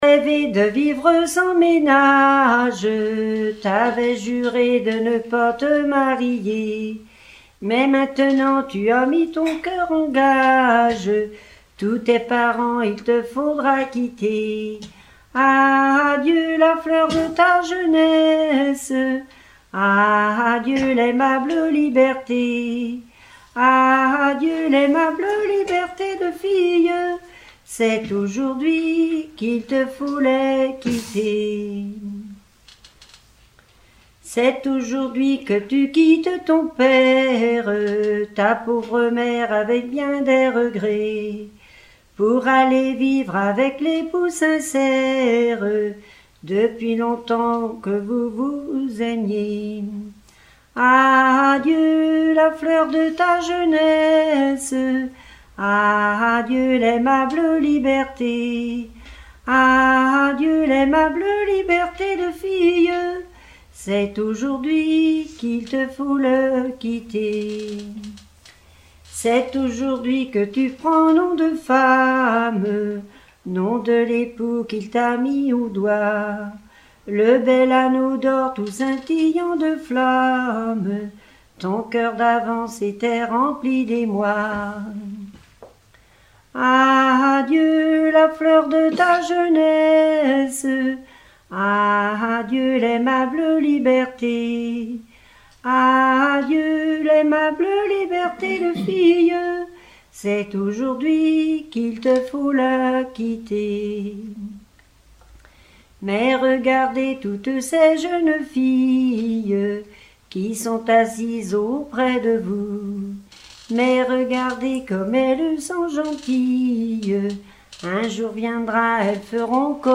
circonstance : fiançaille, noce ;
Genre strophique